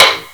taiko-normal-hitwhistle.wav